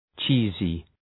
Shkrimi fonetik {‘tʃi:zı}